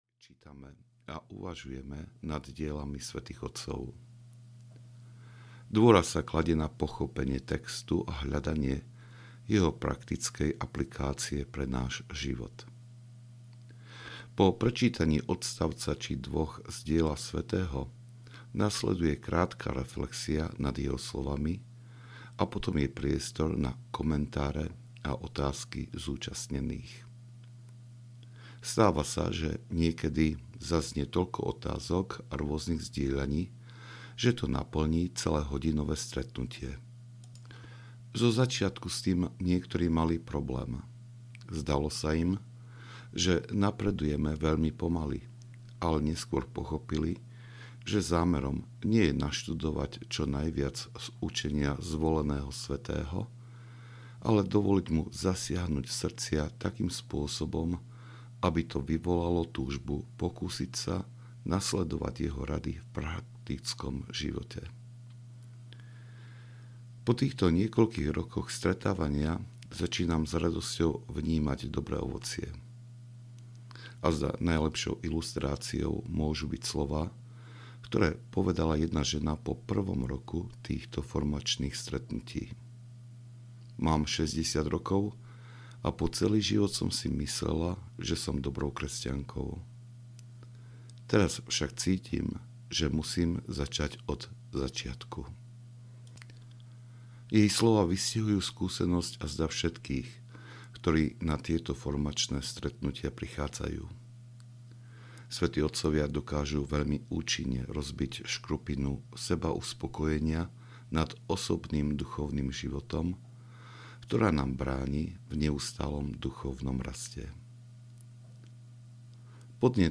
Zrieknutie sa svetského života audiokniha
Ukázka z knihy